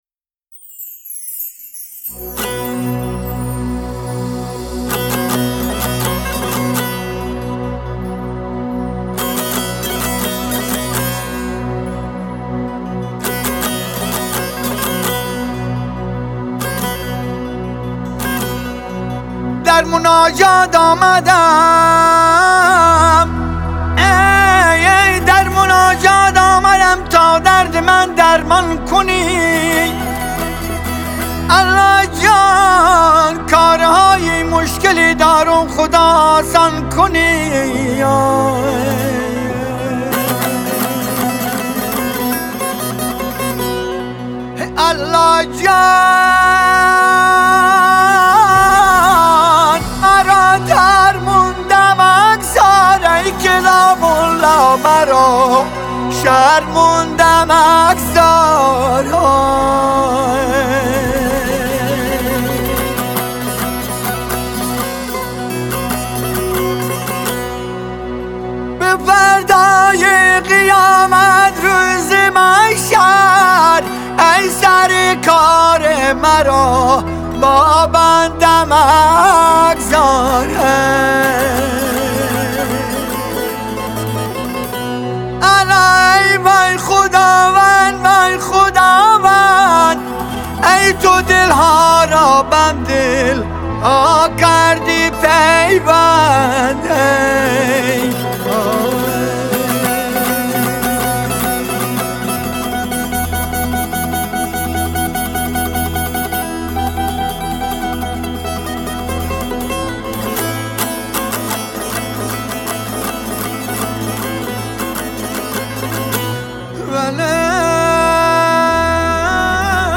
مناجات‌خوانی